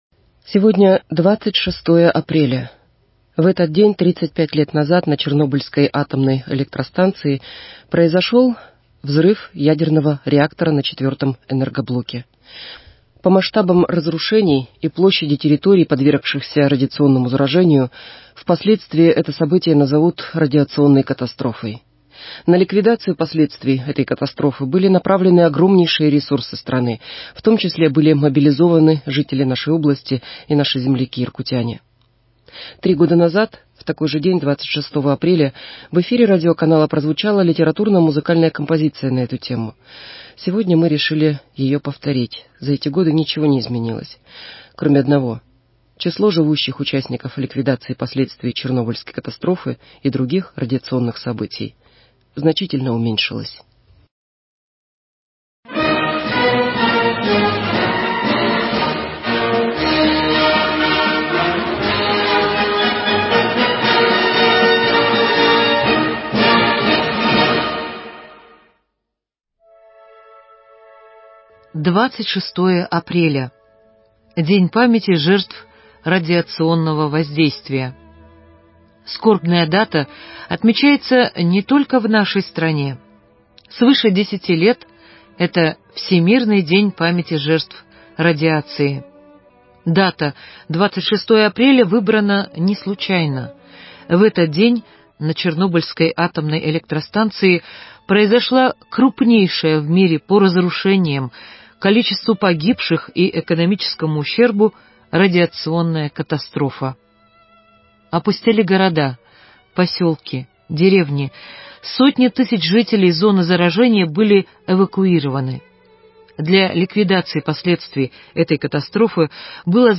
На ликвидацию последствий этой катастрофы были направлены огромнейшие ресурсы страны, в том числе были мобилизованы жители нашей области и наши земляки- иркутяне. 3 года назад, в такой же день 26 апреля в эфире "Подкаст"а прозвучала литературно-музыкальная композиция на эту тему.